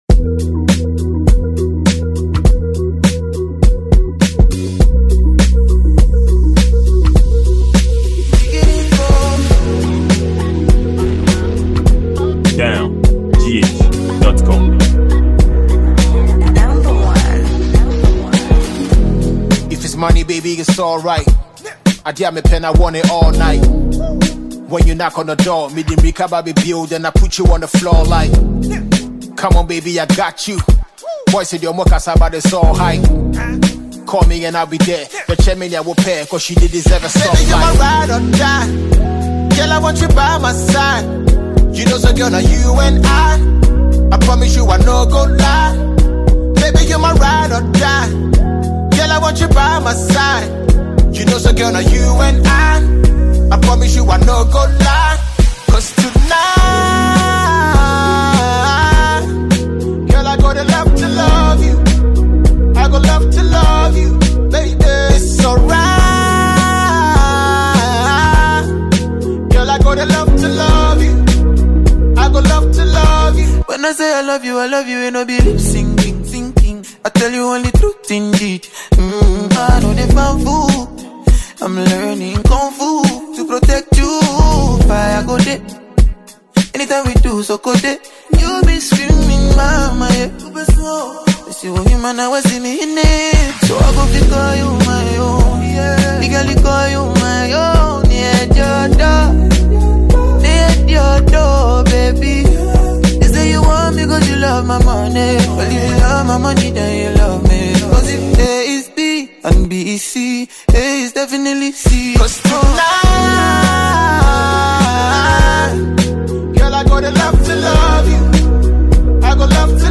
Ghana Music
Ghanaian singer